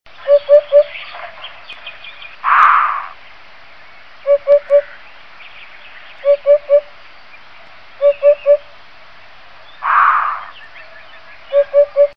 Dudek - Upupa epops